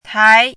chinese-voice - 汉字语音库
tai2.mp3